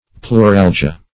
Pleuralgia \Pleu*ral"gi*a\, n. [NL., fr. Gr. ? rib + ? pain.]